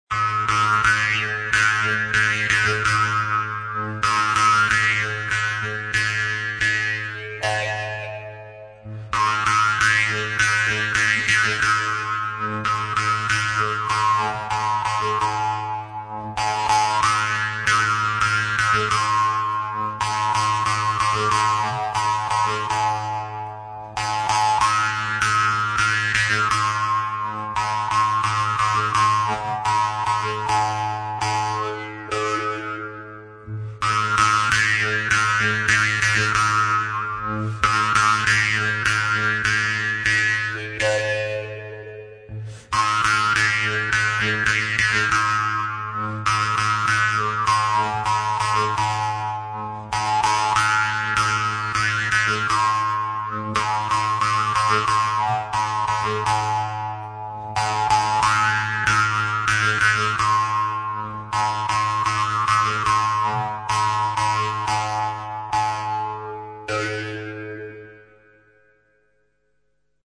Инструментальные пьесы [9]
Переложение для шанкобыза.
Шанкобыз